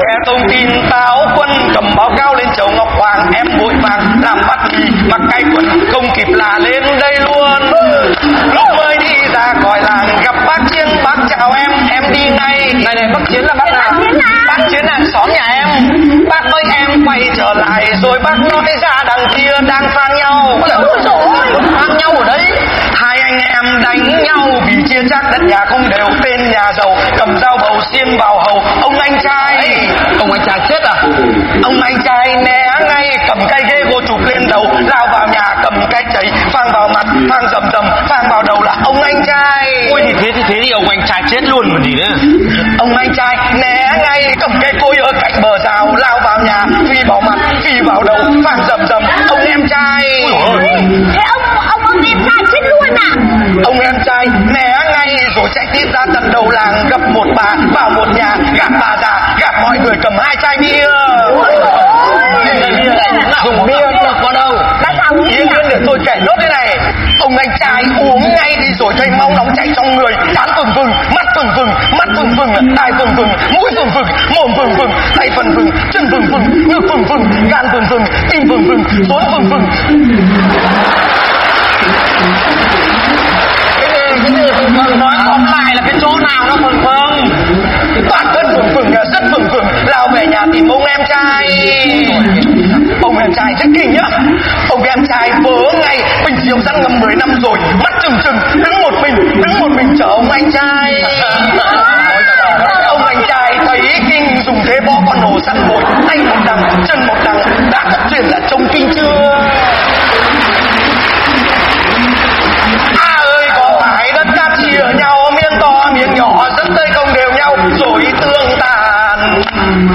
Nhạc Chuông Chế Hài Hước